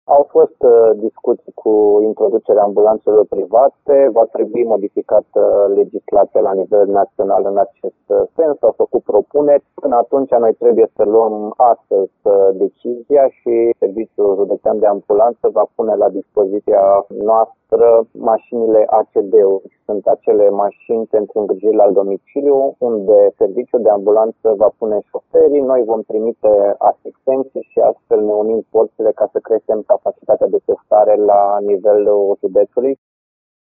Decizia a fost luată pentru că autoritățile fac față tot mai greu creșterii numărului de cazuri, spune directorul DSP Arad, Horea Timiș.